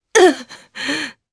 Morrah-Vox_Damage_jp_01.wav